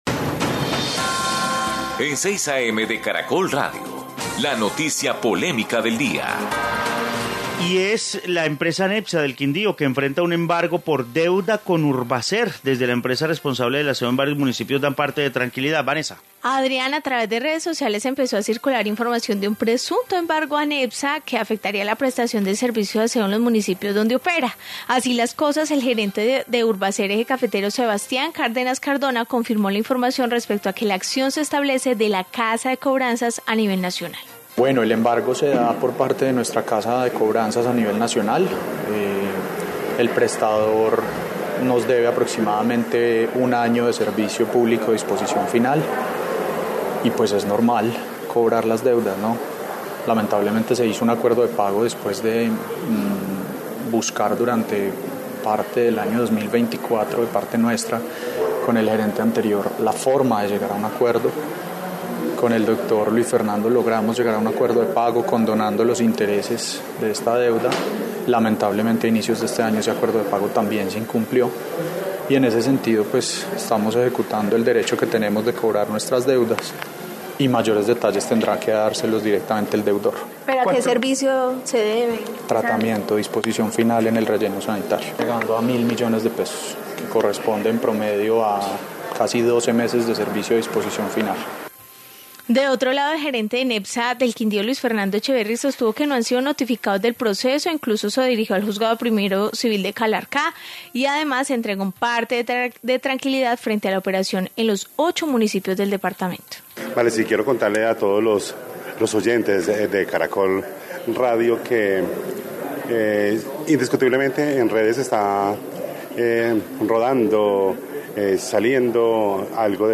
Informe sobre embargo a Nepsa